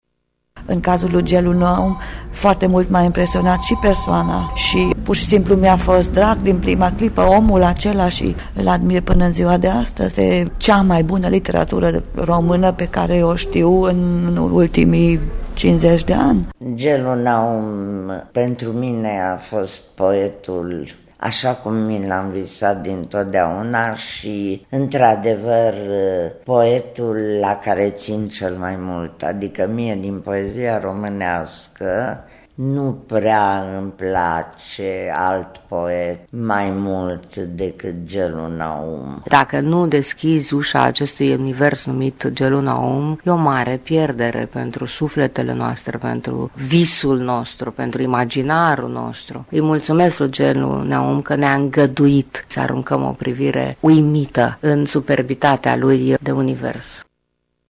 “Cireaşa de pe tort” va fi … vocea lui Gellu Naum, reţinută pe bandă în 1967 (când scriitorul avea 52 de ani), respectiv în anul 2000 (la vârsta de 84 de ani a autorului”Zenobiei”).
“Melancolică, uneori stinsă, alteori dublată de umor reţinut, ironică sau autoironică, simulând deconcertarea, ezitarea”(potrivit Simonei Popescu), vocea scriitorului poate fi auzită pe cele 2 CD-uri încorporate ale cărţii: ”Gellu Naum-Vârsta semnului”e ditat de Casa Radio în 2010 (în seria “Colecţionarul de voci”).
Audiobook-ul se deschide cu poemul “Crusta”: